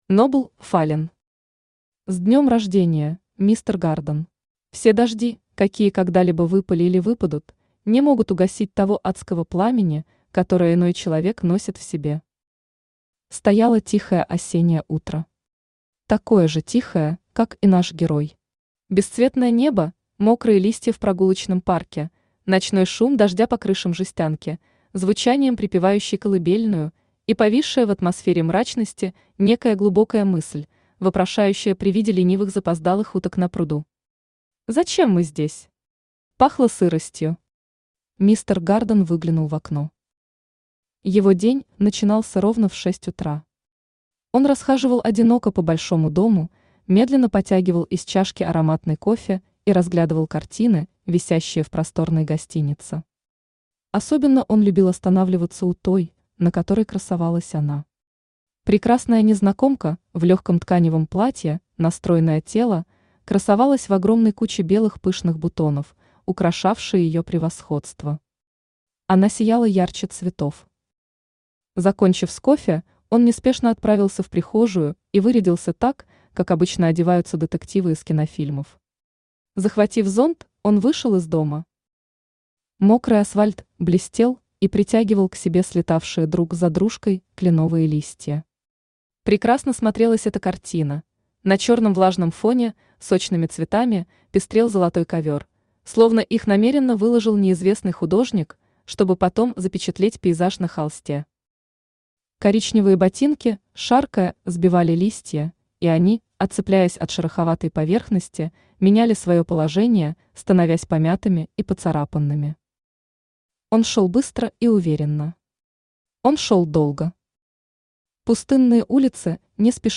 Аудиокнига С днем рождения, мистер Гарден!
Автор Нобл Фаллен Читает аудиокнигу Авточтец ЛитРес.